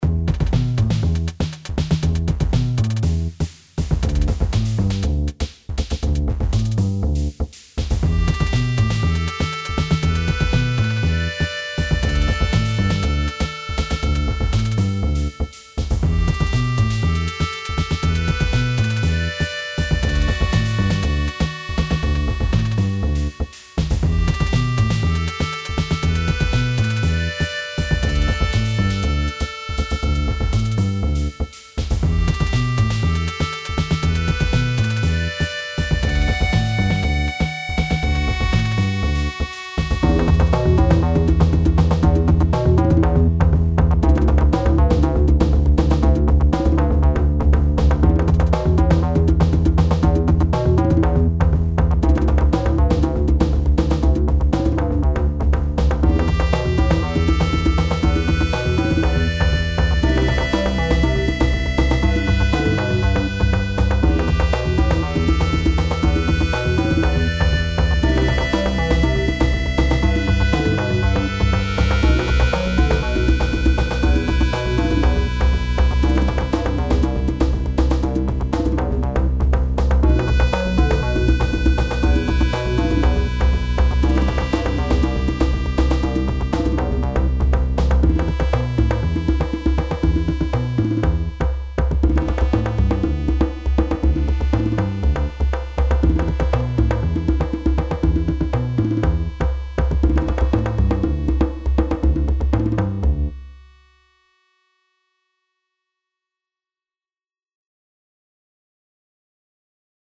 The drums are excellent throughout though.
Has a fun improvisatory feel.
The drums were groovy though, good job there.